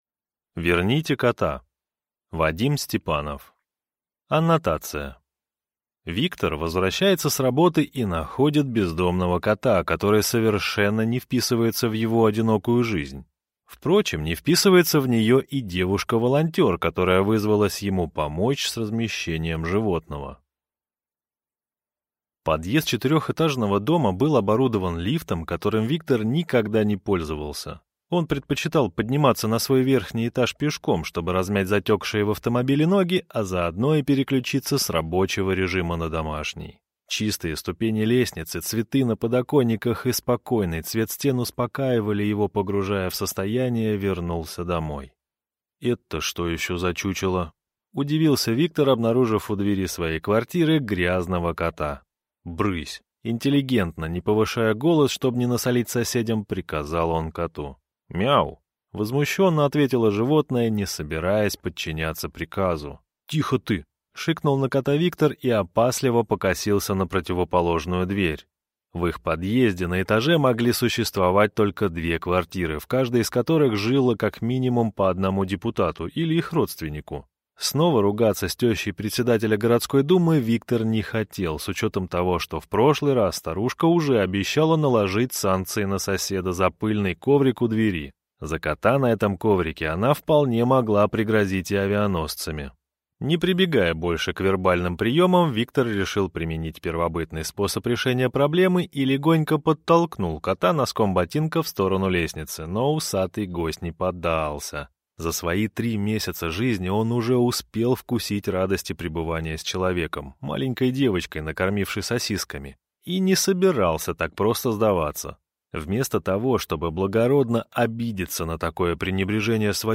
Аудиокнига Верните кота | Библиотека аудиокниг